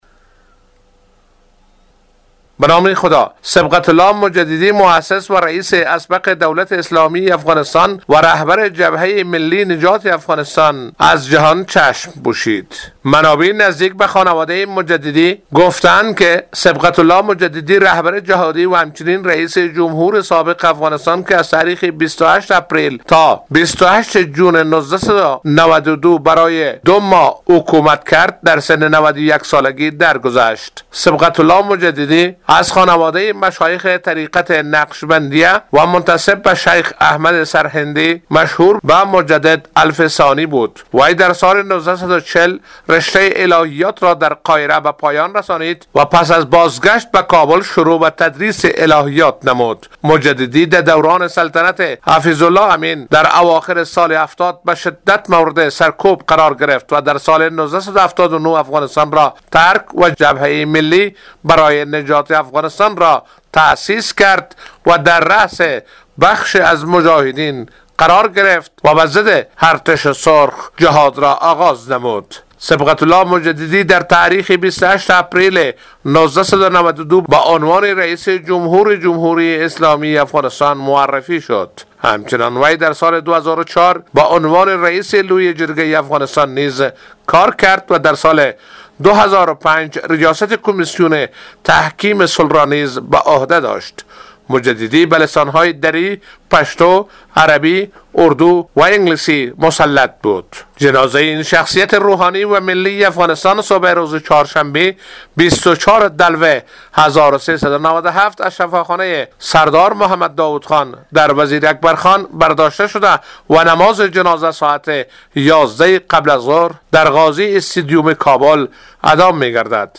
به گزارش خبرنگار رادیو دری، حزب نجات ملی افغانستان که مجددی ریاست آن را برعهده داشت اعلام کرد مراسم خاکسپاری صبغت الله مجددی فردا چهارشنبه در کابل برگزار می شود.